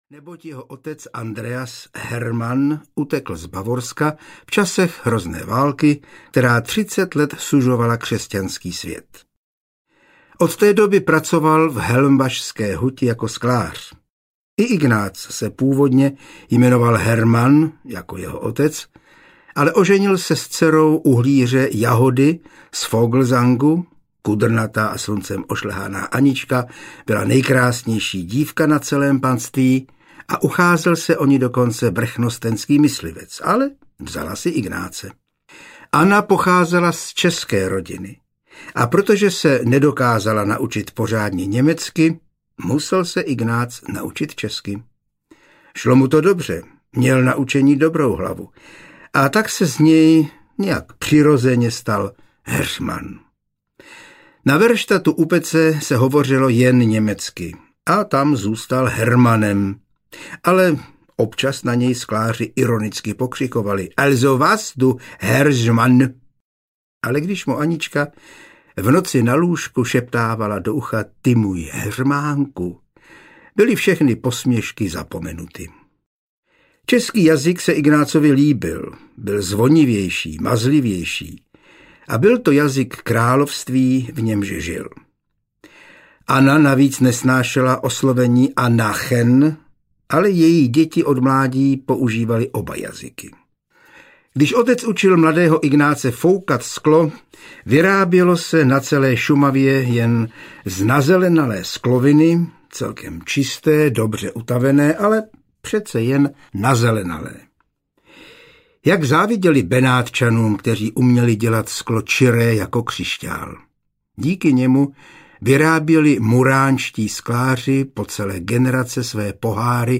Křišťálový klíč I. - Falknovská huť audiokniha
Ukázka z knihy